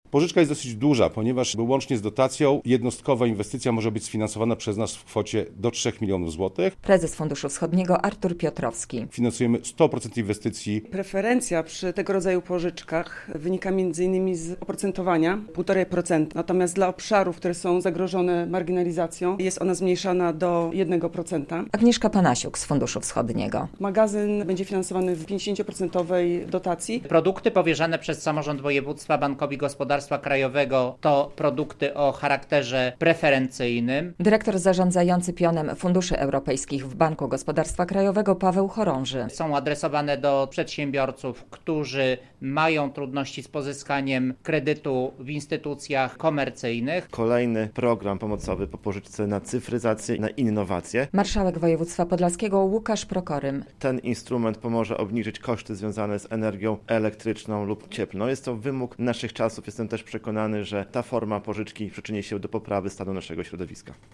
relacja
Pożyczek będzie udzielał Fundusz Wschodni, który wcześniej zawarł dwie umowy z Bankiem Gospodarstwa Krajowego w tej sprawie - poinformowano w poniedziałek na konferencji prasowej w urzędzie marszałkowskim w Białymstoku.